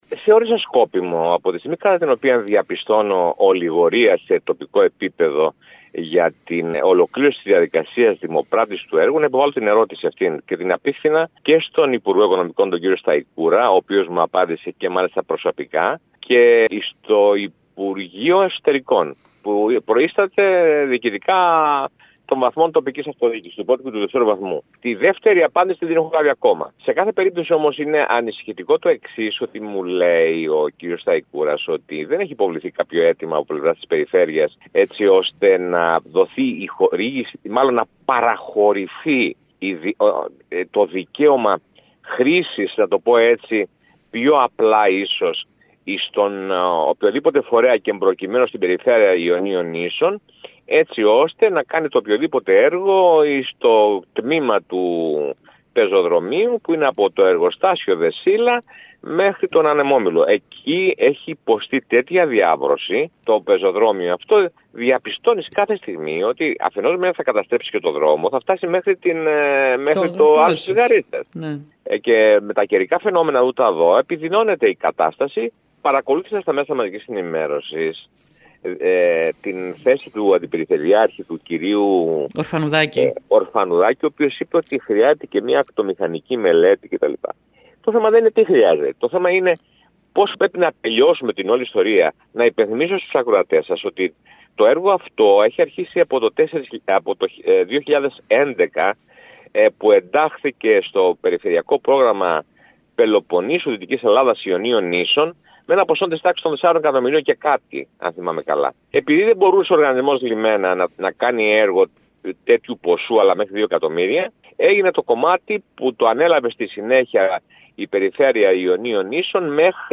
“Αυτό δημιουργεί ερωτηματικά για τις προθέσεις της τοπικής διοίκησης” σχολιάζει ο βουλευτής και καλεί την περιφέρεια Ιονίων Νήσων να προχωρήσει σε συνεργασία με τον ΟΛΚΕ και το Υπουργείο ώστε να προχωρήσει το έργο.